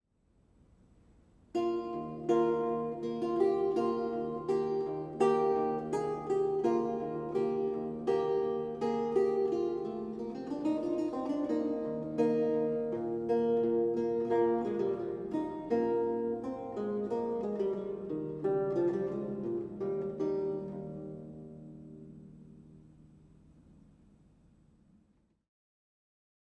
a 16th century lute music piece originally notated in lute tablature